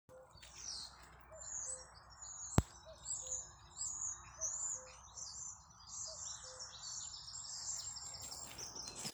Dzeguze, Cuculus canorus
Ziņotāja saglabāts vietas nosaukumsBauskas nov Vecumnieku pag.
StatussDzied ligzdošanai piemērotā biotopā (D)